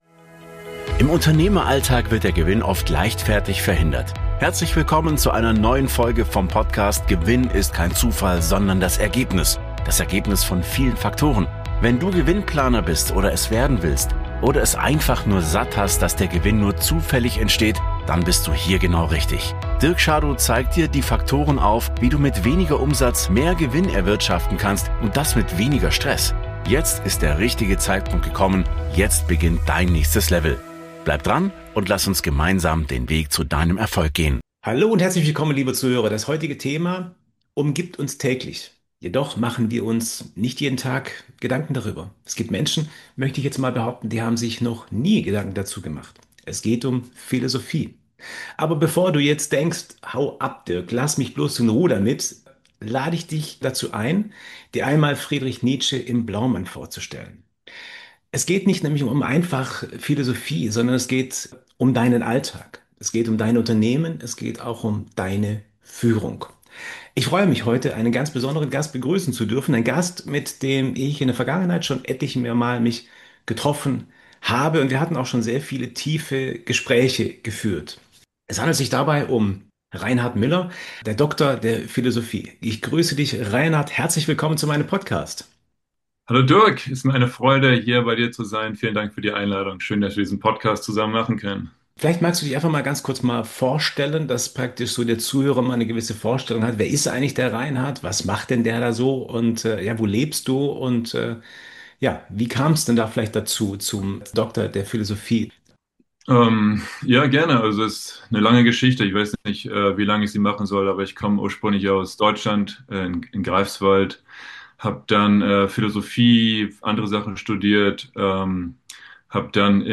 Ein Gespräch zwischen Praxis und Theorie – mit vielen Impulsen für Führungskräfte und Unternehmer.